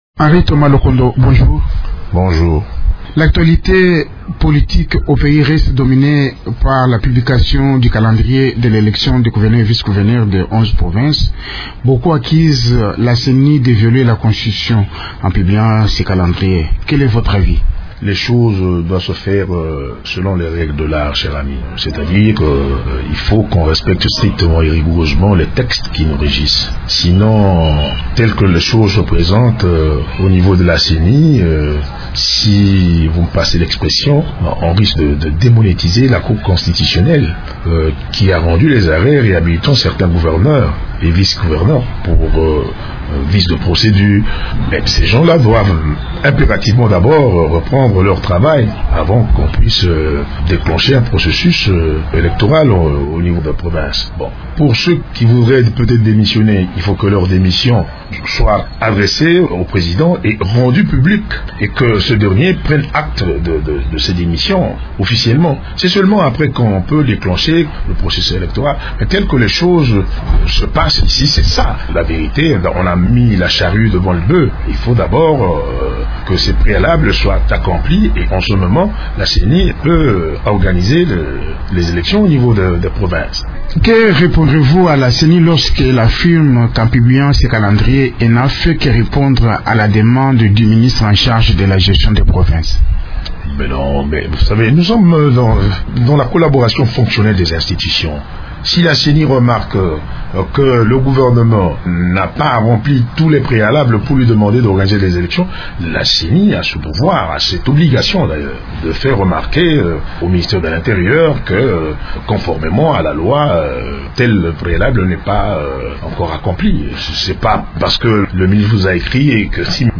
Le député de la majorité, Henry Thomas Lokondo est l’invité de Radio Okapi ce matin.